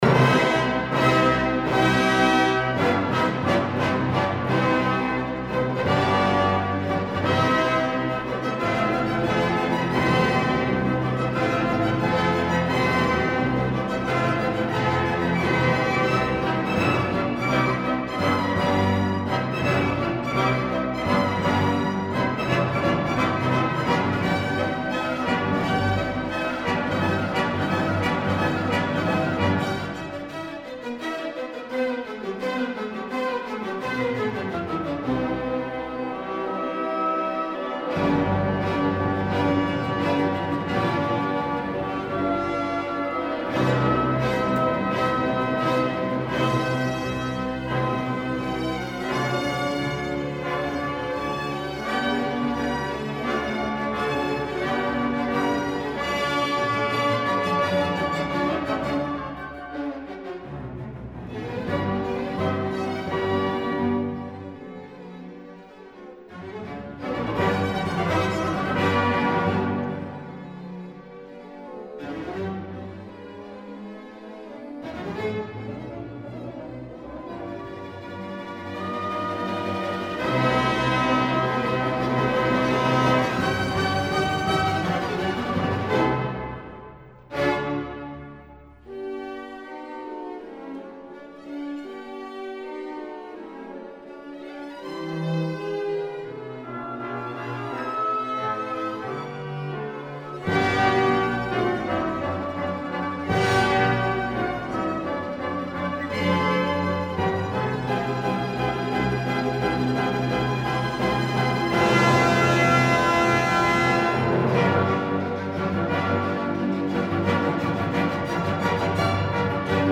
سایت موسیقی کلاسیک